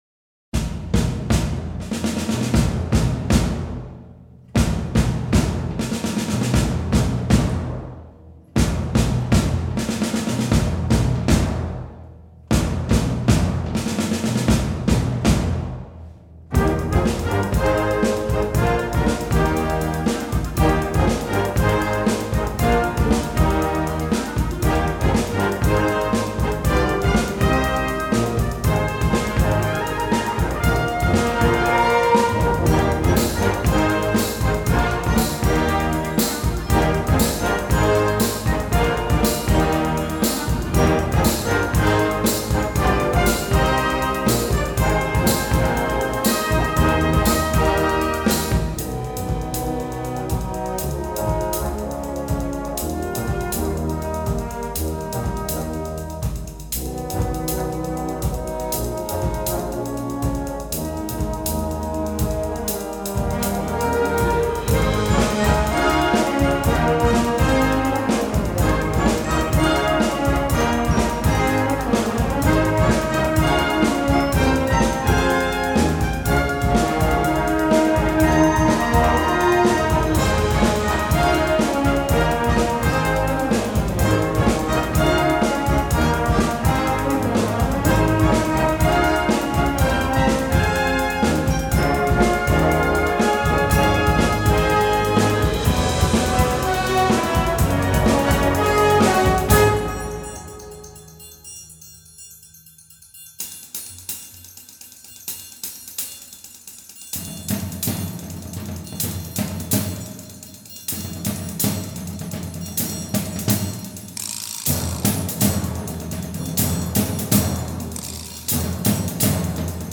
Gattung: Eröffnungswerk für Blasorchester
Besetzung: Blasorchester